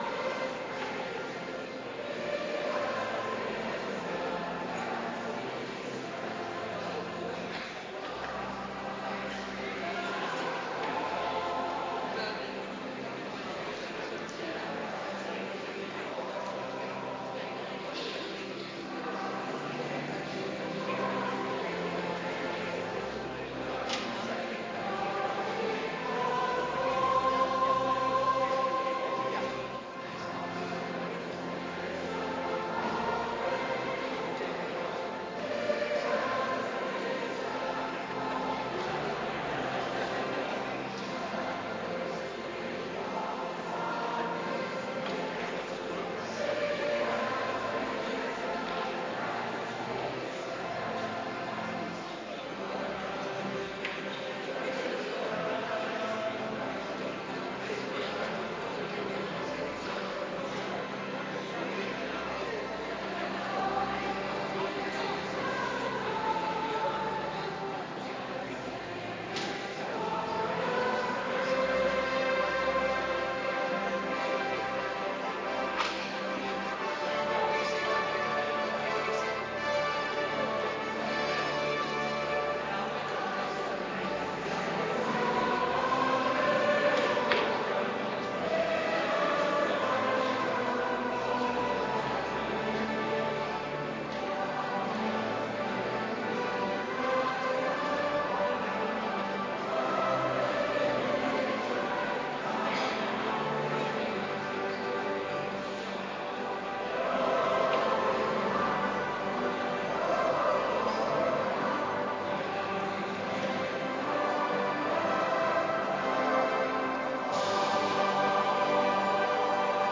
Adventkerk Zondag week 19